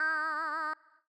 Audio / SFX / Characters / Voices / BardHare / BardHare_06.wav